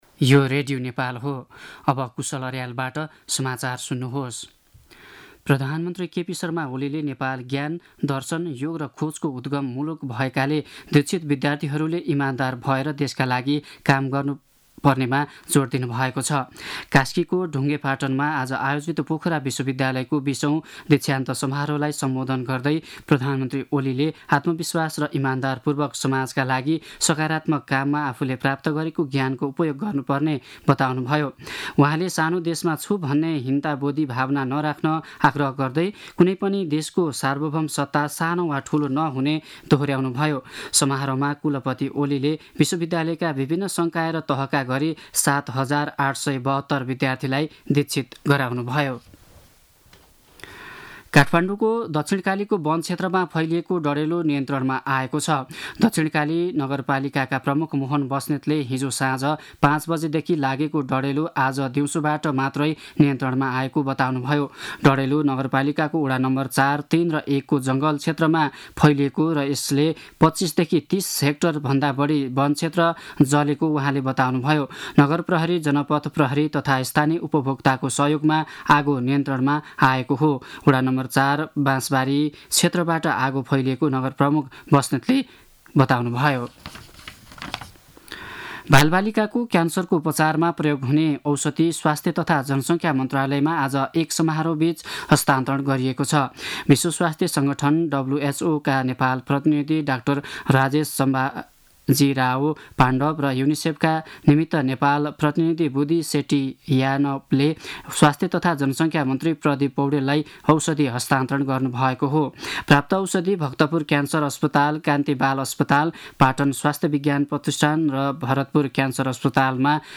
दिउँसो ४ बजेको नेपाली समाचार : ९ फागुन , २०८१
4-pm-news-6.mp3